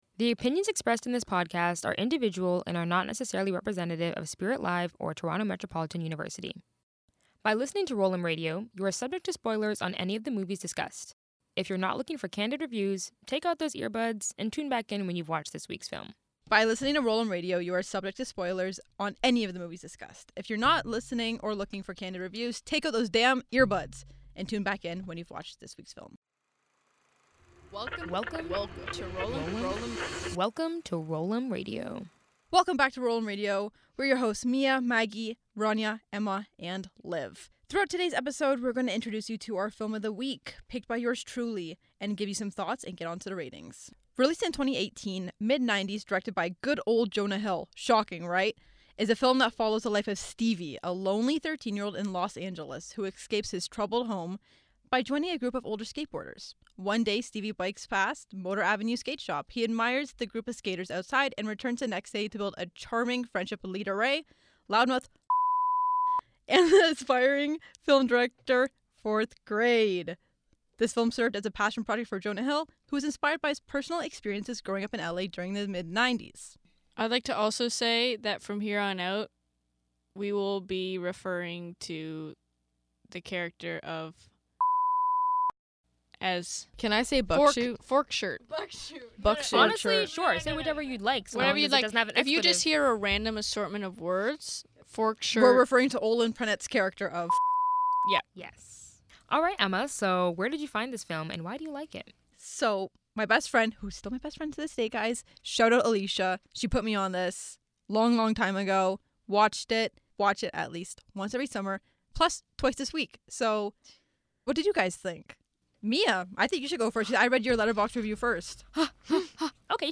One movie, five critics, and one final verdict.